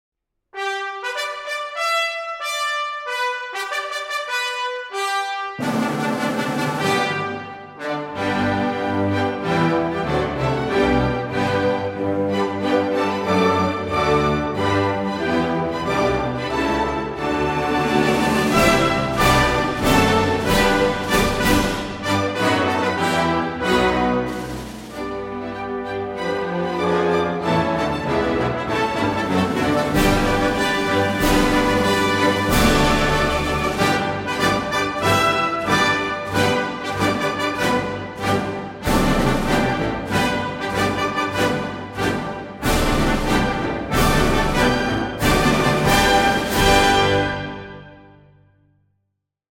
Official Recording of the National Anthem – Orchestra Version